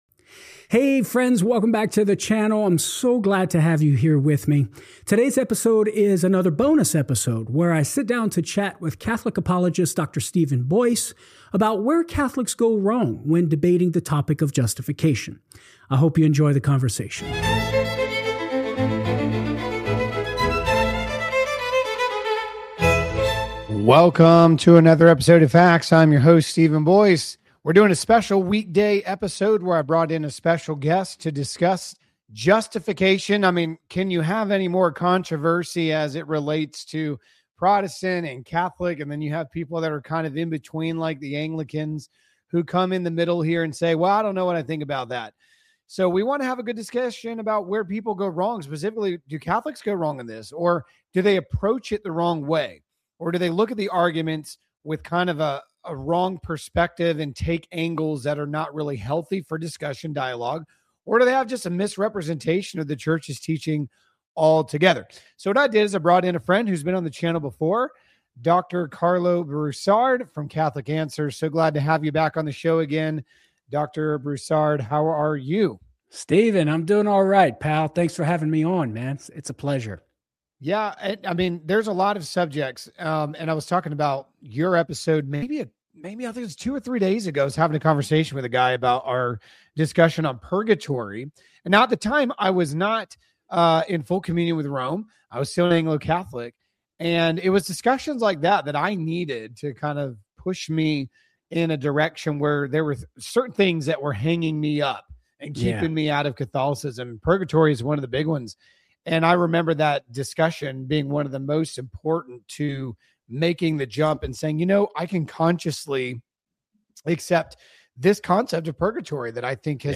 I hope you enjoy the conversation.